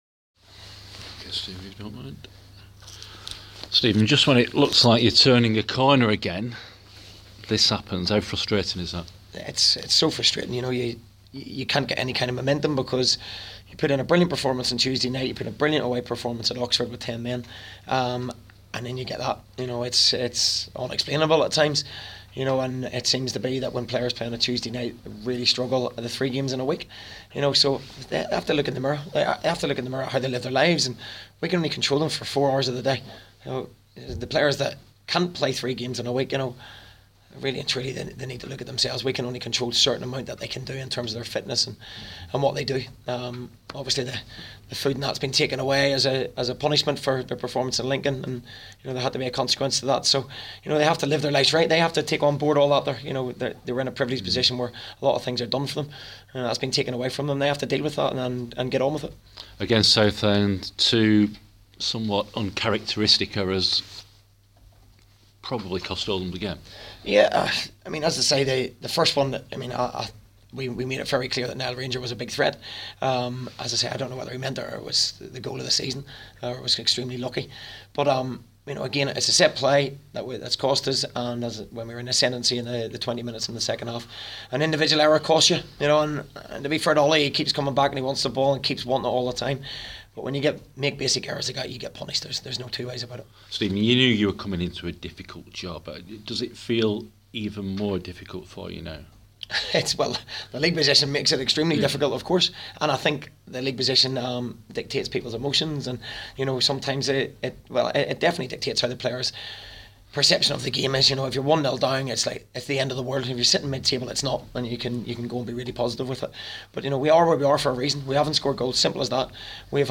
Oldham Athletic manager Steve Robinson shares his thoughts on the 0-2 defeat at home to Southend.